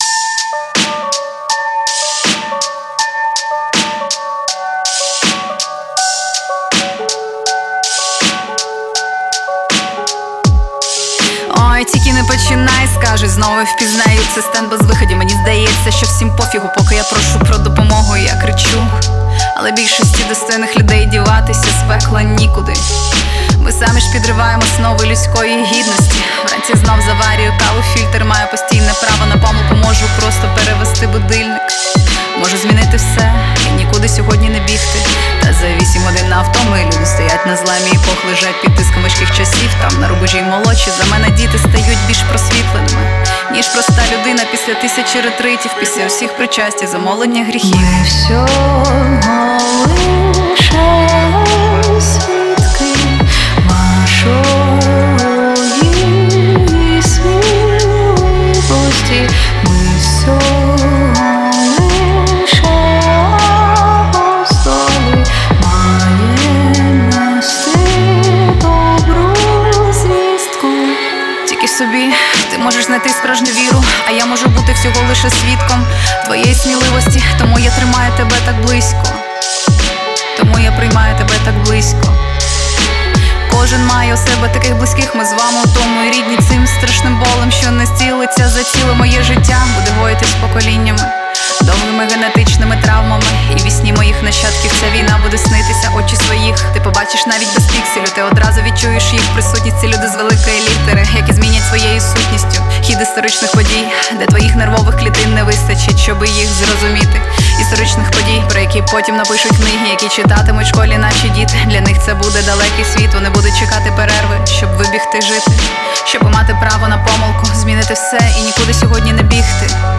хіп-хоп